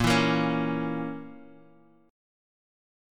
Bbdim chord